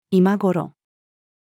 about-this-time-female.mp3